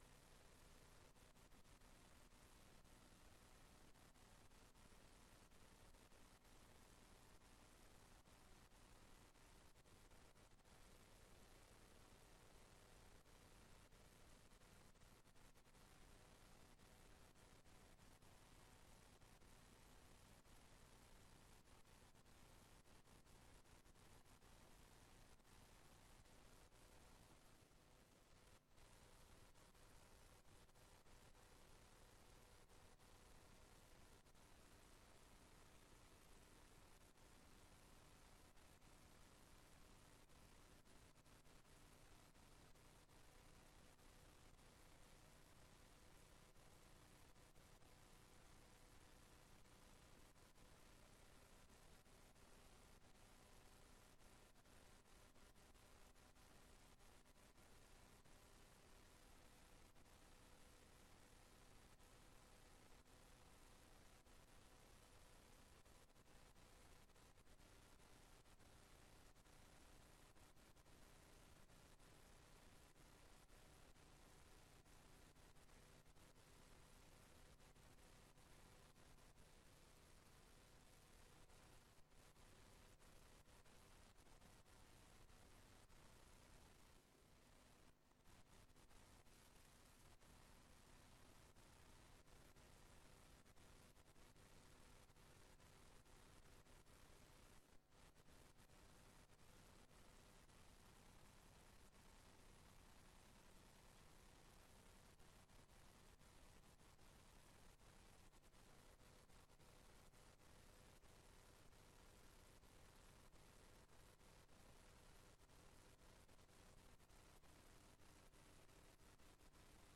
Raadsbijeenkomst 11 november 2025 19:30:00, Gemeente Tynaarlo
Locatie: Raadszaal